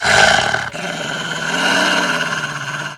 bdog_groan_0.ogg